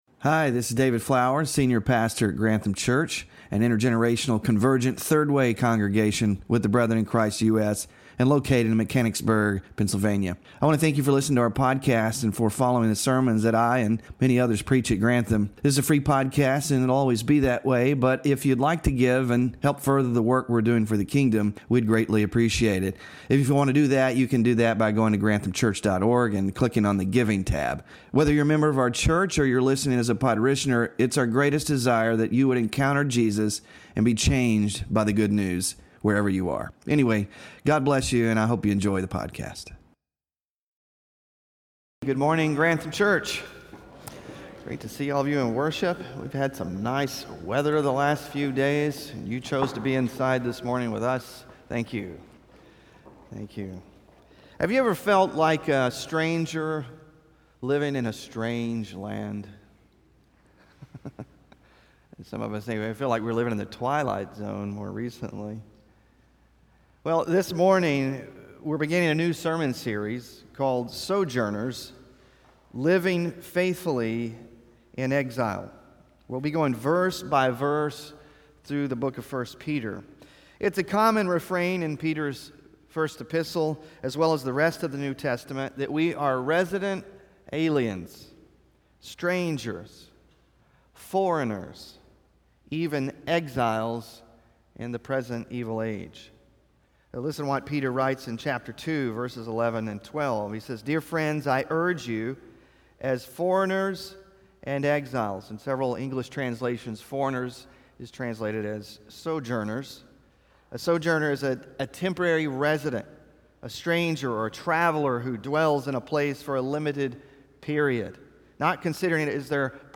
Sermon Focus: Peter opens his letter with encouragement to scattered “exiles” across Asia Minor. He begins by reminding these believers that their identity is in Christ, who has given us new birth into a living hope through the resurrection of Jesus Christ from the dead.